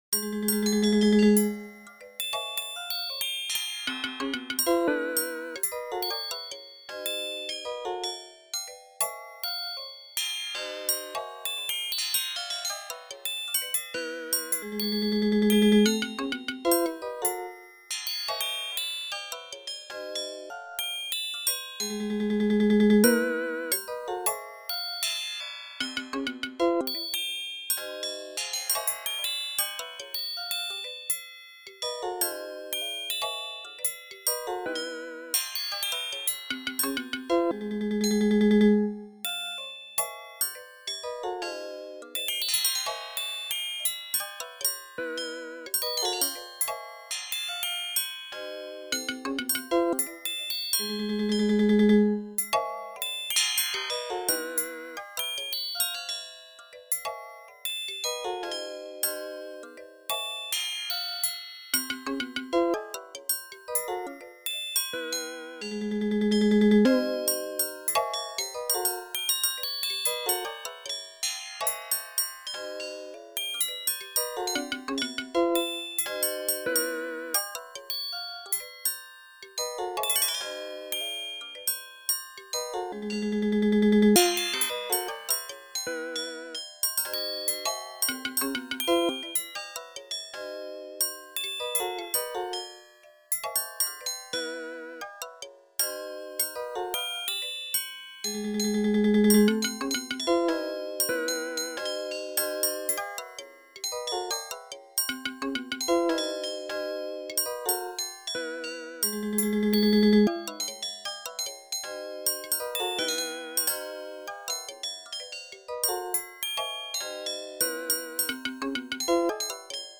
Four machine performances
These four recordings were all created using the Assistant Performer alone, without a live performer.
2. speed as notated in the score, minimum ornament chord duration set to 100 milliseconds.